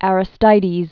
(ărĭ-stīdēz) Known as "the Just." 530?-468?